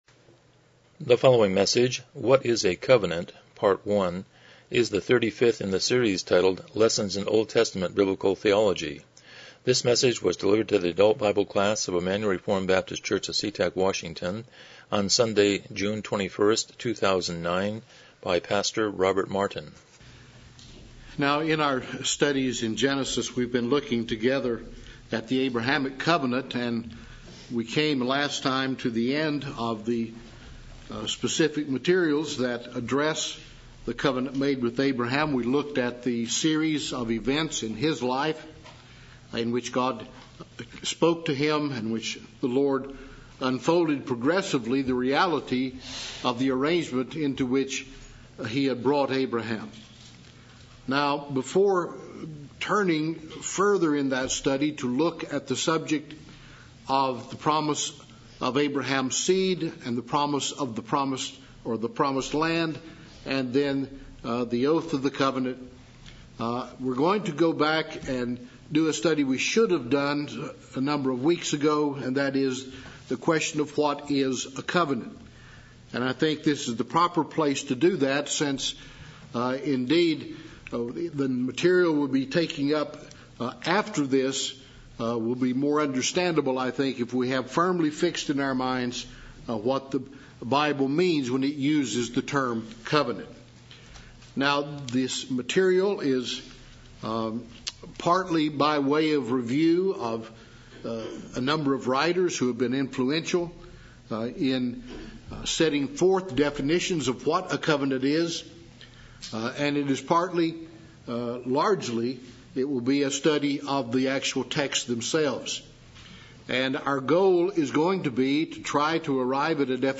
Lessons in OT Biblical Theology Service Type: Sunday School « 64 Chapter 10.4